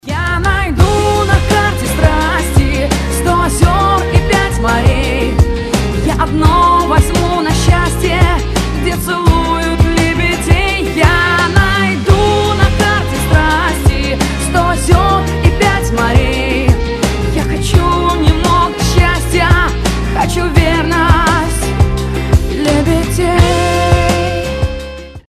• Качество: 256, Stereo
поп
женский вокал
dance